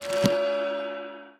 enchant2.ogg